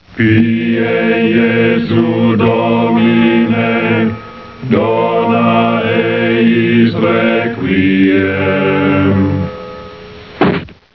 The monks chanting.